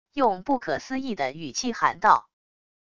用不可思议的语气喊道wav音频